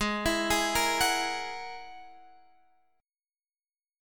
G#7sus2#5 chord